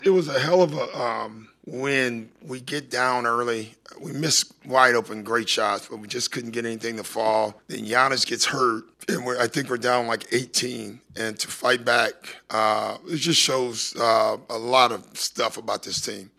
Rivers talked about the win.